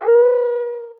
ahh3.ogg